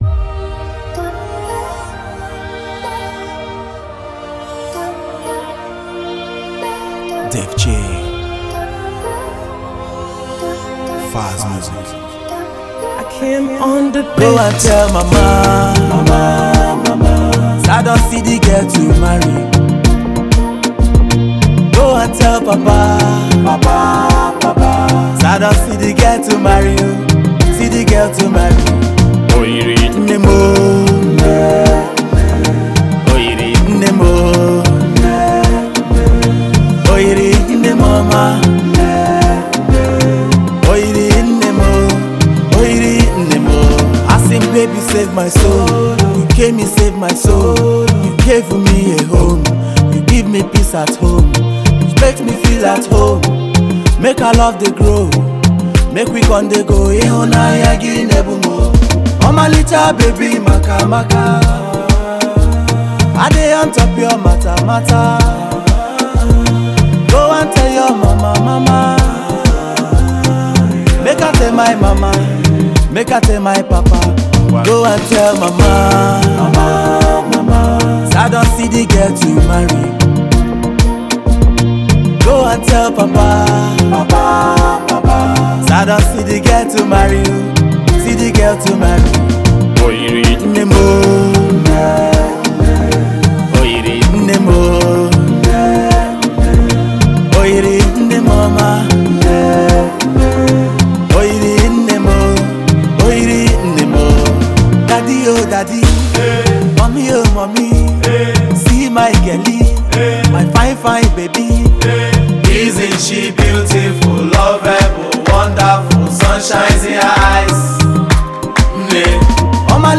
soul, R&B, and highlife afrobeat sounds